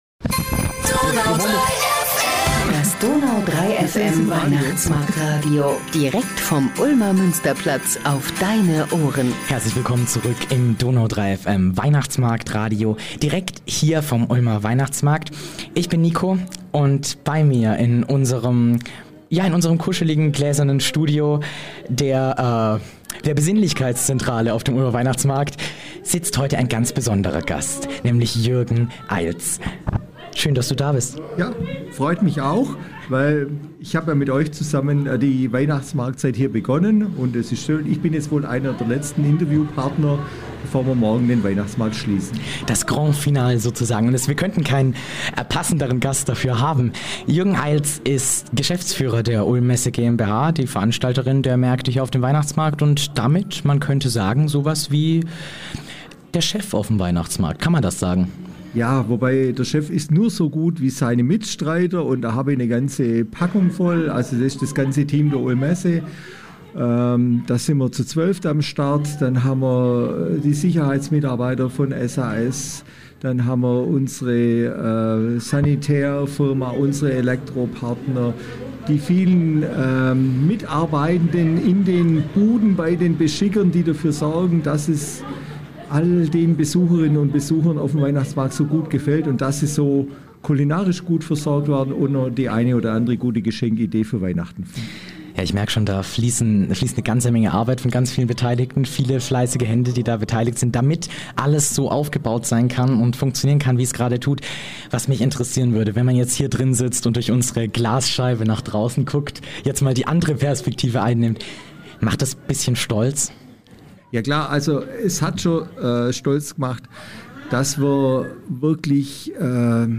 Wir verabschieden uns für jetzt aus dem gläsernen Studio und freuen uns schon darauf, wenn es wieder losgeht mit dem DONAU 3 FM-Weihnachtsmarktradio direkt vom Ulmer Münsterplatz.